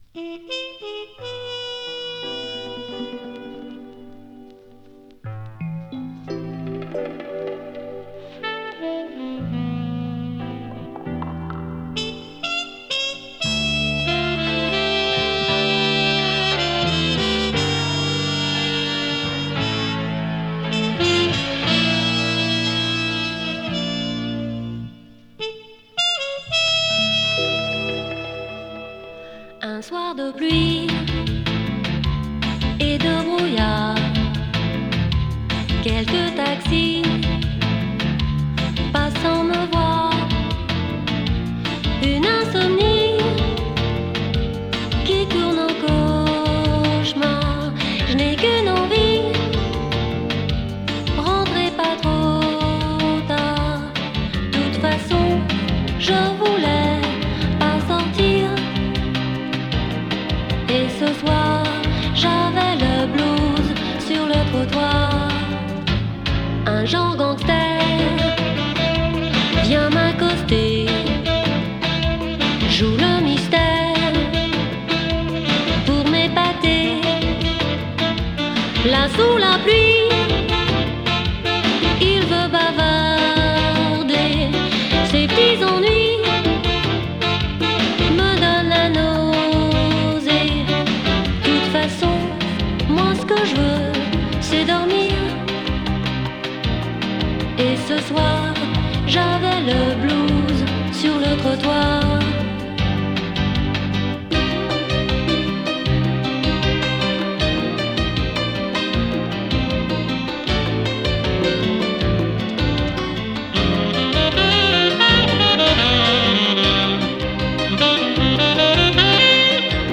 女性Vo
フレンチ ボッサ ジャズ
哀愁漂うフレンチ・ジャジー・ボッサ！男性SAX奏者と女性シンガーのデュオによる代表曲。